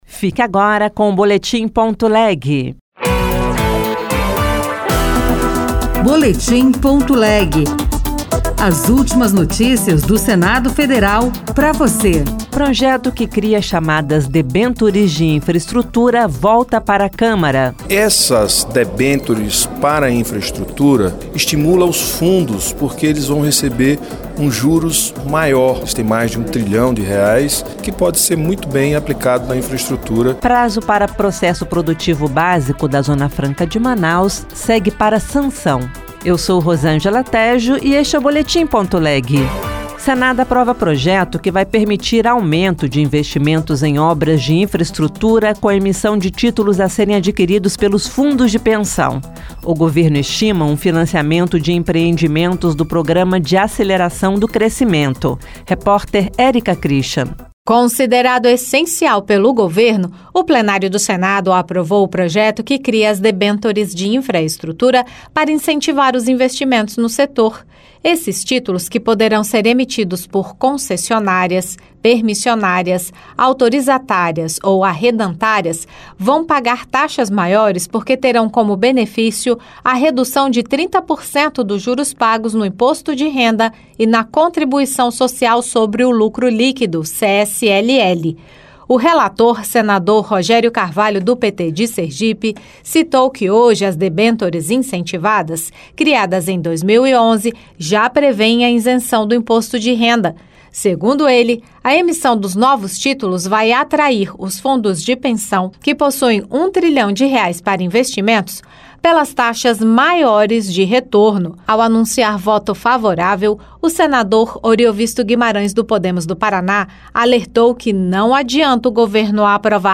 RadioAgência Senado